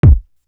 Feel Me Kick.wav